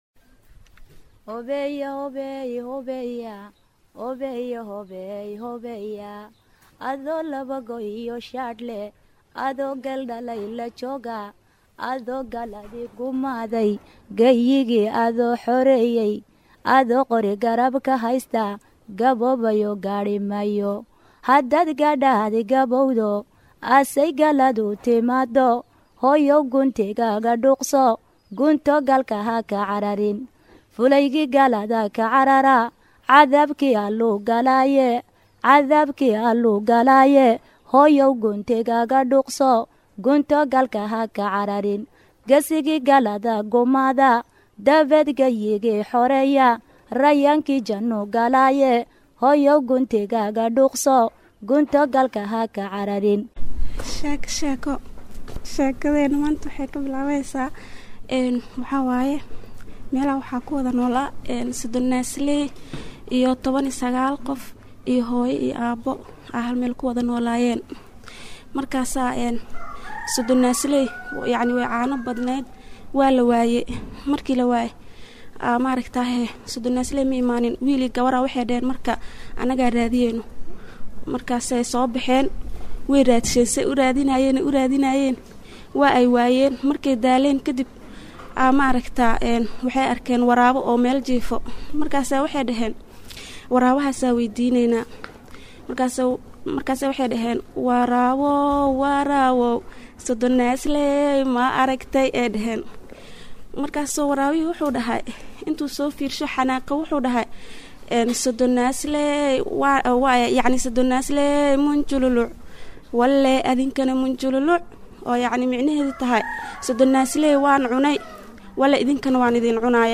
Barnaamijka Tarbiyada Caruurta ee Jimco weliba ka baxa warbaahinta Islaamiga ah ee Al-Furqaan, waa barnaamij tarbiyo iyo barbaarin oo ku socda caruurta iyo waalidiintooda, waxaana xubnaha ku baxa kamid ah xubin loogu magacdaray Sheeko-Xariir, oo ay soo jeedinayaan hooyooyinka Soomaaliyeed.